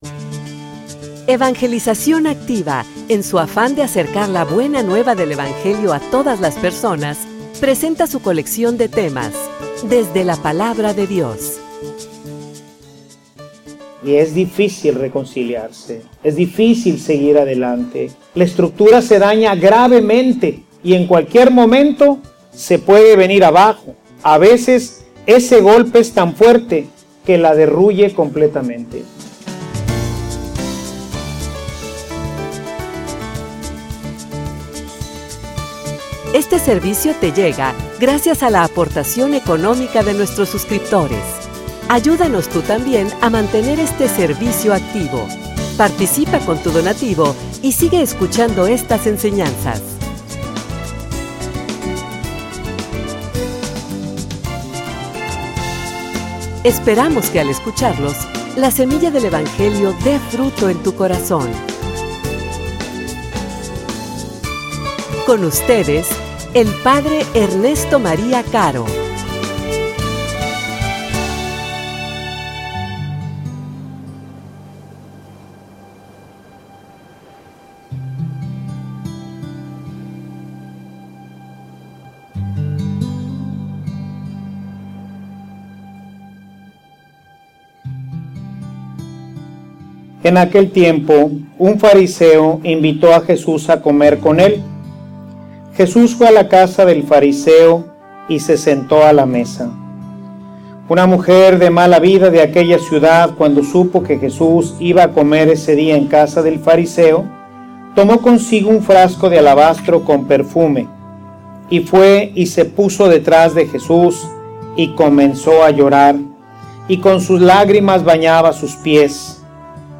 homilia_Te_confiesas_o_te_reconcilias.mp3